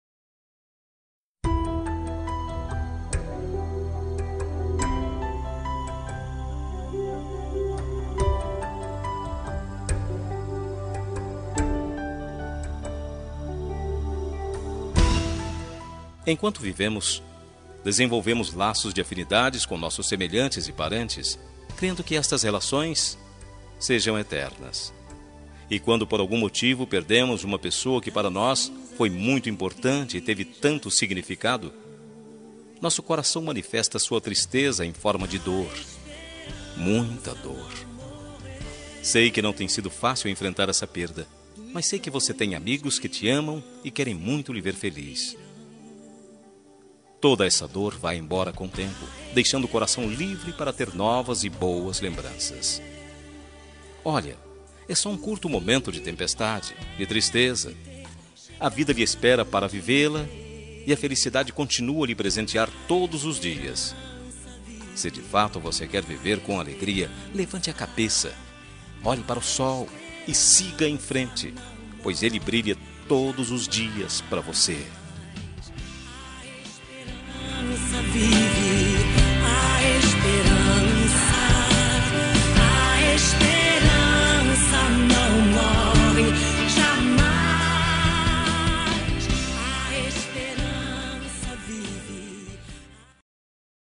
Telemensagem Pêsames – Voz Masculina – Cód: 5260